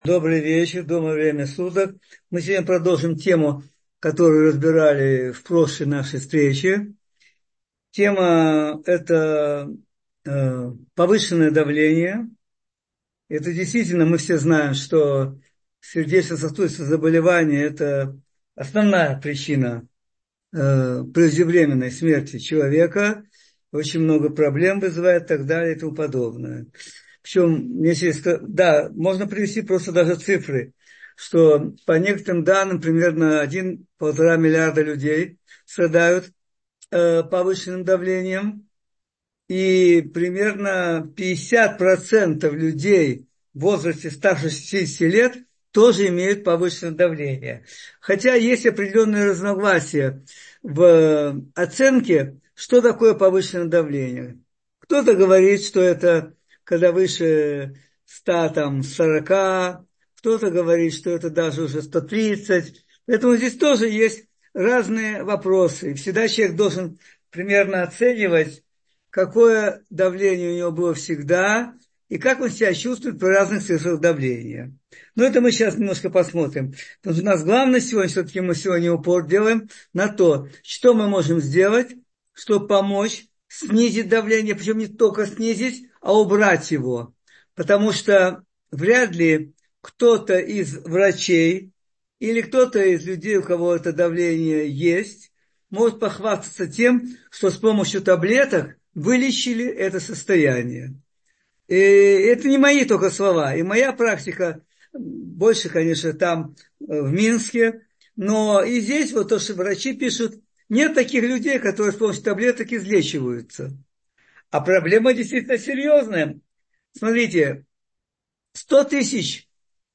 Как лечить не-болезнь без лекарств — слушать лекции раввинов онлайн | Еврейские аудиоуроки по теме «Проблемы и вопросы» на Толдот.ру